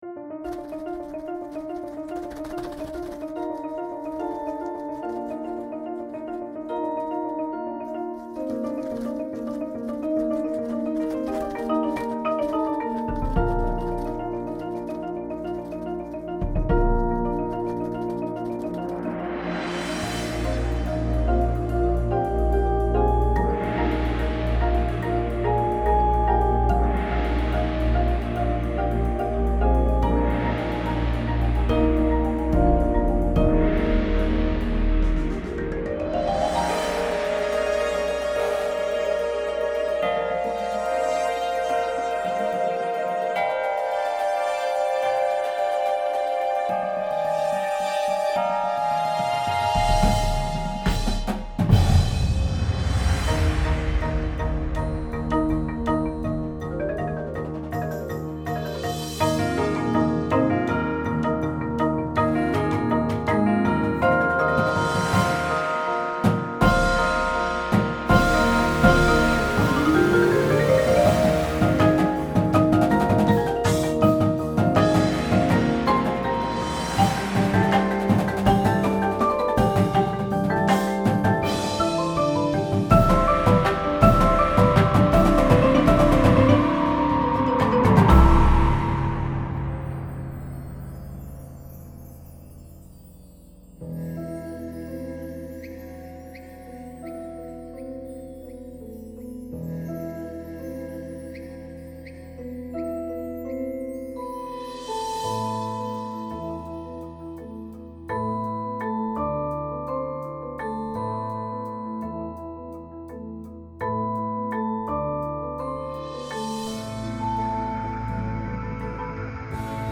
• Marching Snare Drum
• Marching Tenors (4, 5, and 6 drum parts available)
• Marching Bass Drum (3, 4, and 5 drum parts available)
• Marching Cymbals
Front Ensemble
• Two Synthesizer parts (Mainstage patches included)
• Bass Guitar
• Marimba 1/2
• Xylophone
• Glockenspiel/Chimes
• Vibraphone 1
• Drumset